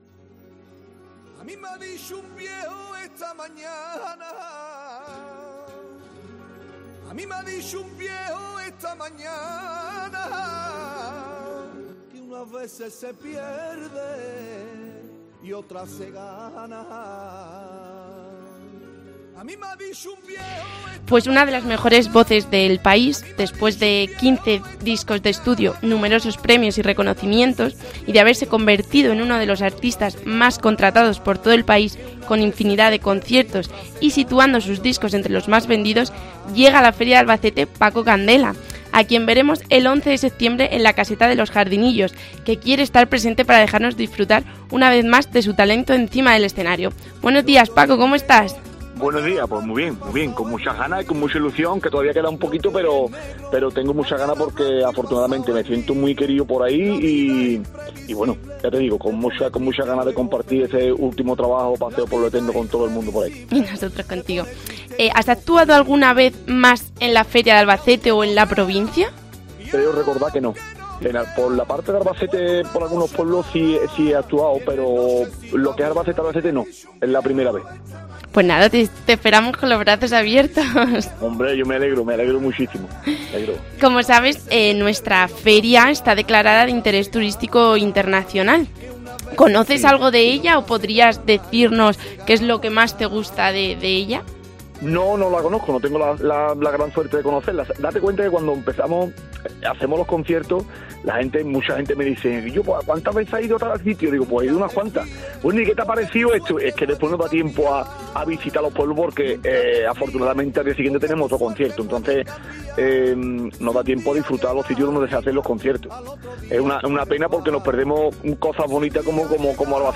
Entrevista Paco Candela